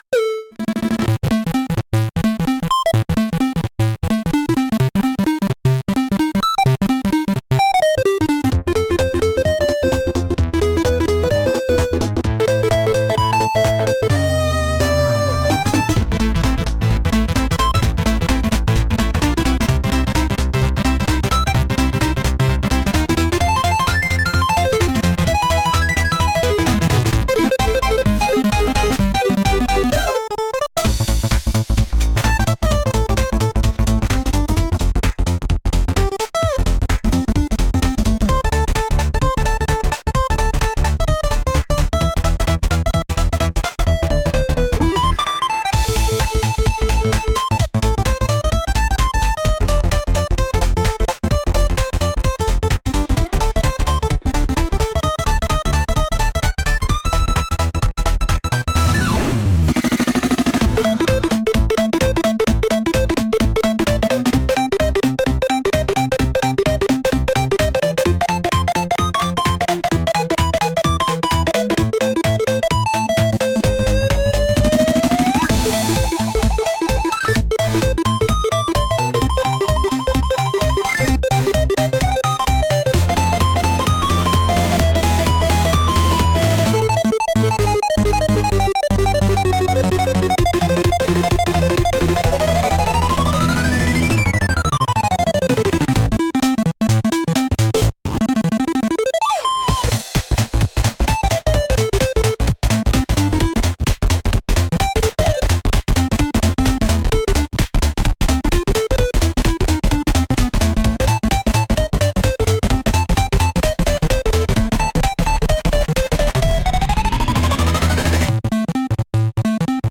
Lyrics : There are none!